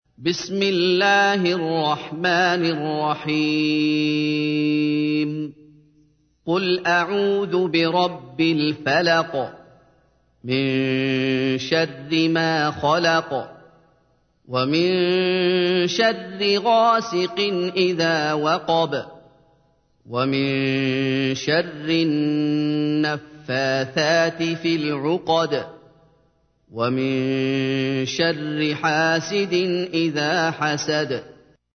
تحميل : 113. سورة الفلق / القارئ محمد أيوب / القرآن الكريم / موقع يا حسين